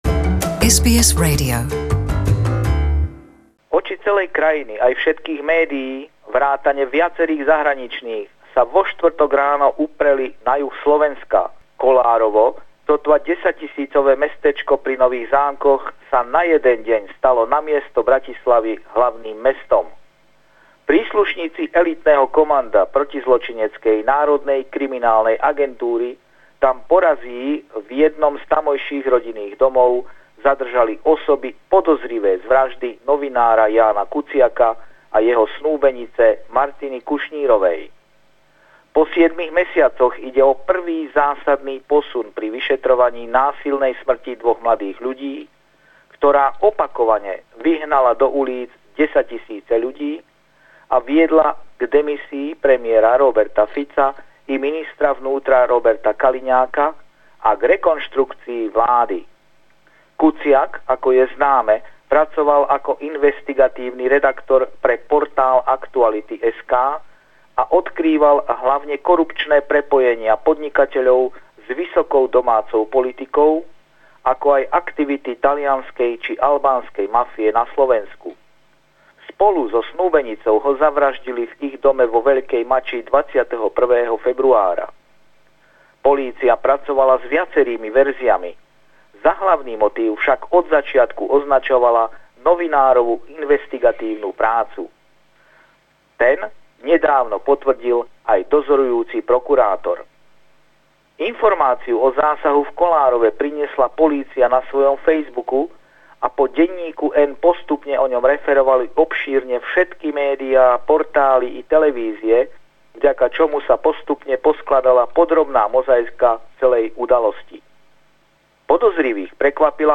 Slovenská polícia zadržala a obvinila troch ľudí z úkladnej vraždy novinára Jána Kuciaka a jeho priateľky Martiny Kušnírovej. Nateraz nie je známe, kto si ich vraždu objednal, a tisíce Slovákov opäť vyšli do ulíc, aby protestovali proti vláde a korupcii. Pravidelný telefonát týždňa